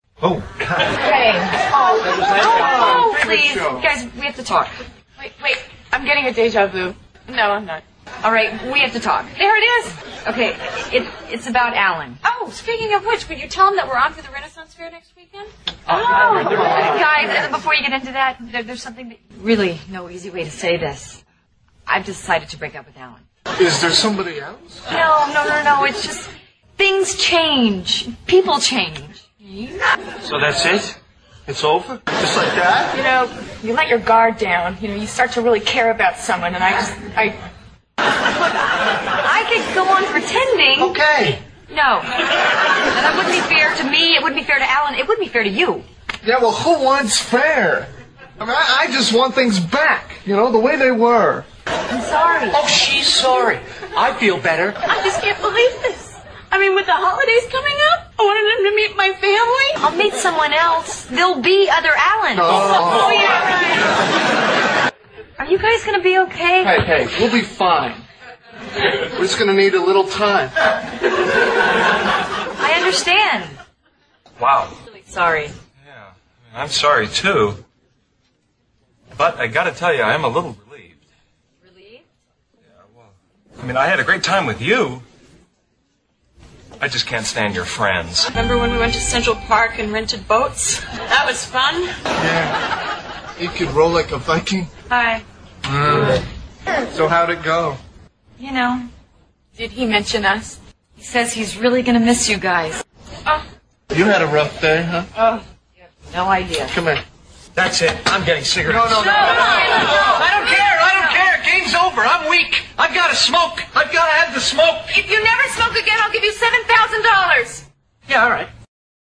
在线英语听力室老友记精校版第1季 第32期:飞来横财(10)的听力文件下载, 《老友记精校版》是美国乃至全世界最受欢迎的情景喜剧，一共拍摄了10季，以其幽默的对白和与现实生活的贴近吸引了无数的观众，精校版栏目搭配高音质音频与同步双语字幕，是练习提升英语听力水平，积累英语知识的好帮手。